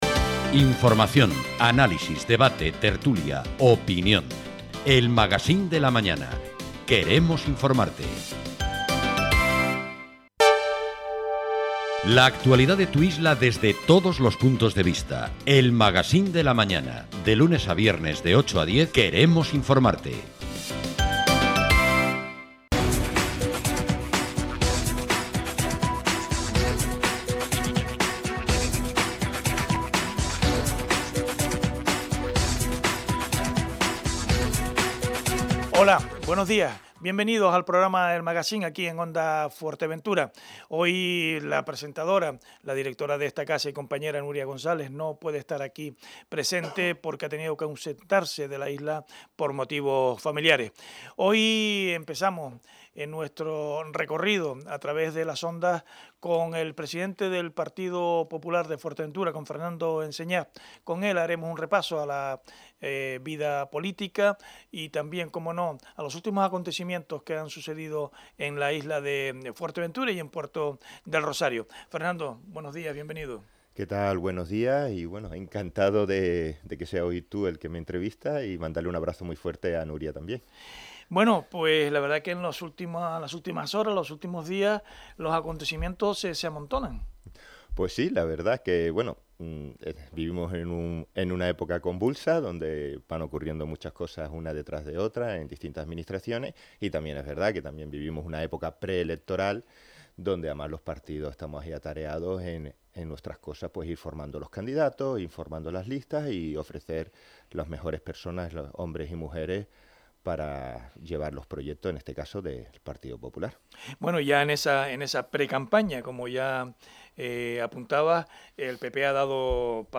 Además, ha repasado la actualidad y la entrevista se puede escuchar aquí: